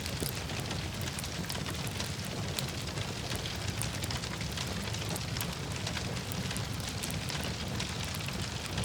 fire-1.ogg